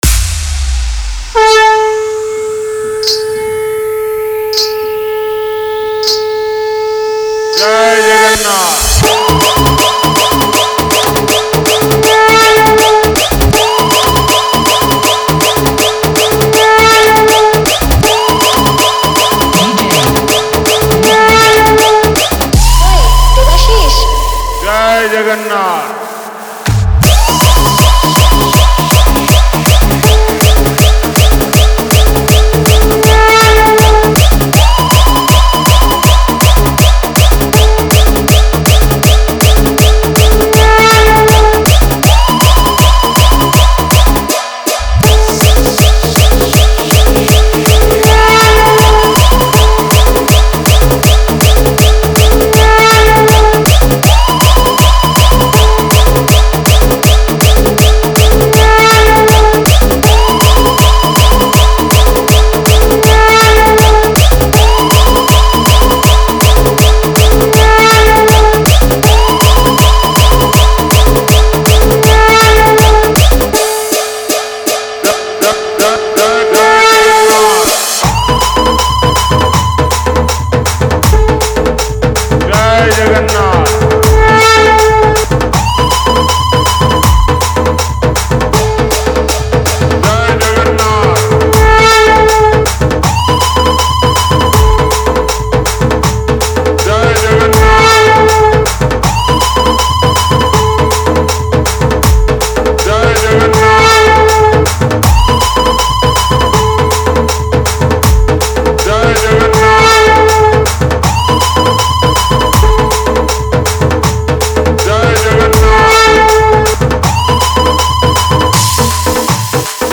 Bhajan Dj Song Collection 2021 Songs Download